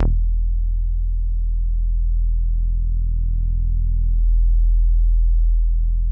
WAVEBASS  E2.wav